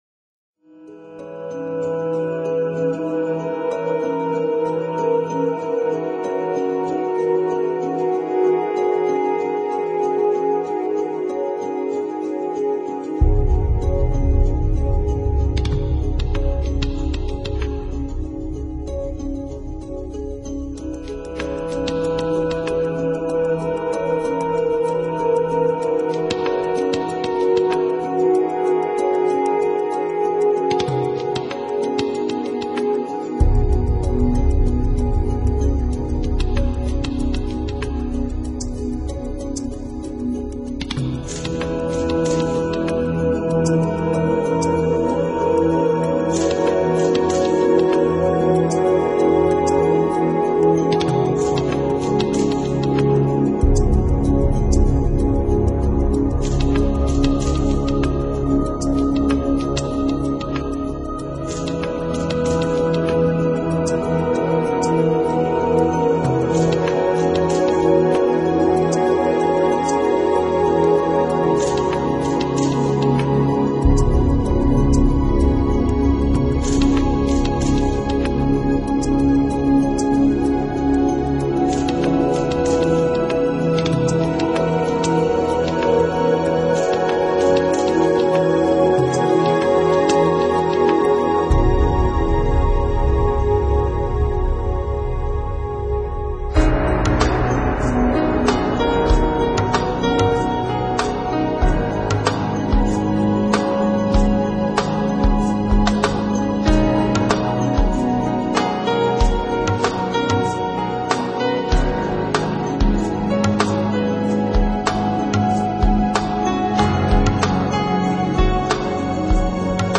音乐类型：NewAge 新世纪 音乐风格：Contemporary,Instrumental